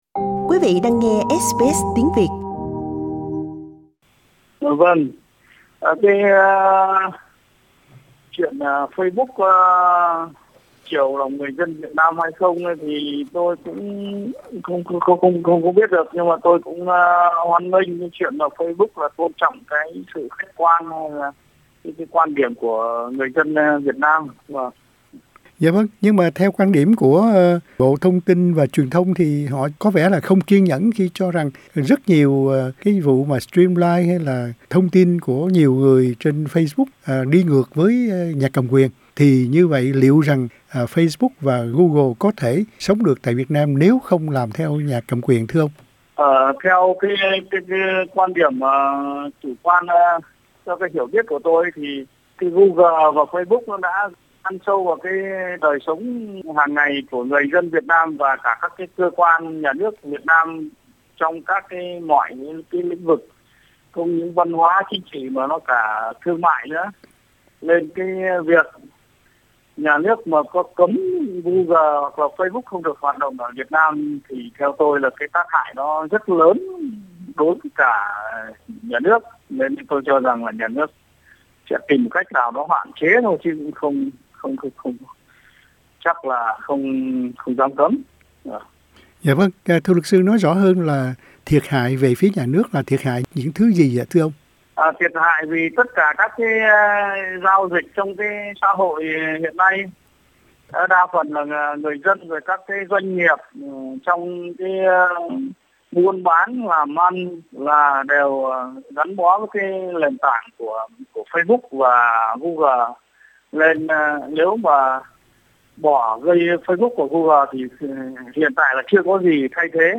phỏng vấn